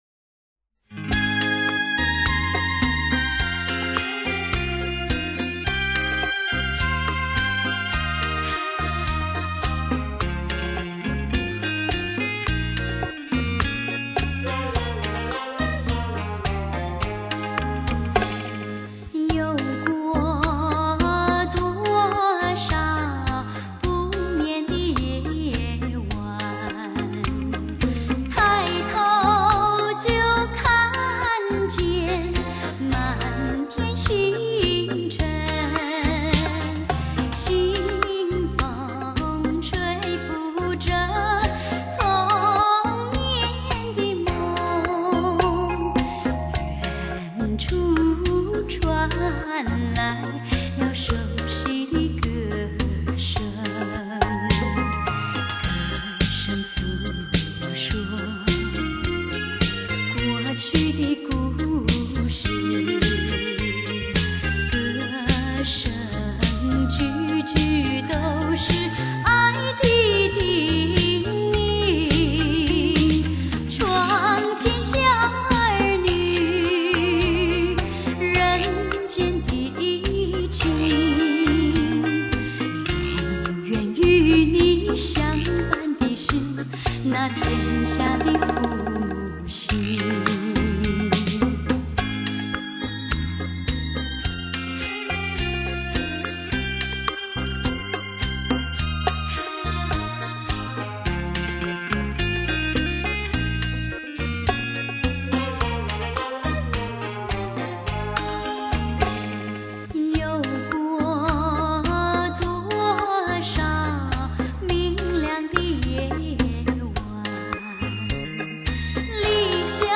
全新台湾风格“那卡西”编曲
高传真身历声环绕数位音效
音场定位清晰
为专业音场测试天碟